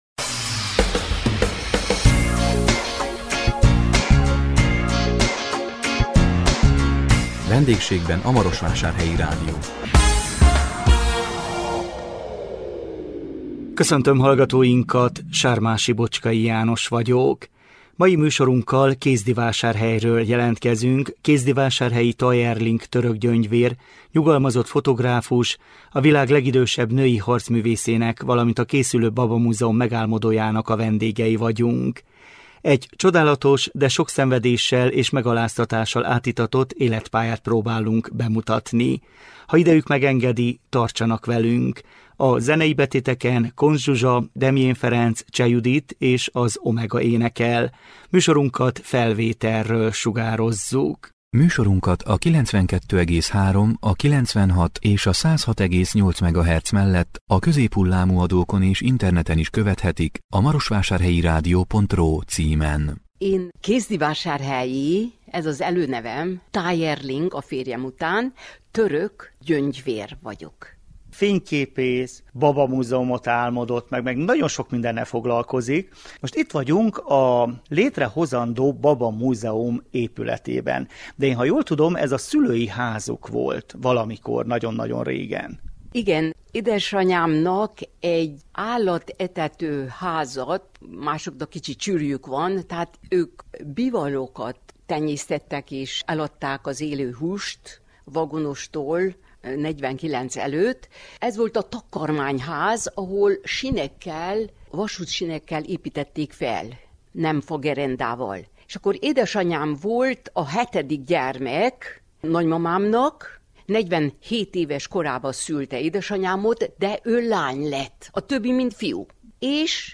A 2026 január 15-én közvetített VENDÉGSÉGBEN A MAROSVÁSÁRHELYI RÁDIÓ című műsorunkkal Kézdivásárhelyről jelentkeztünk,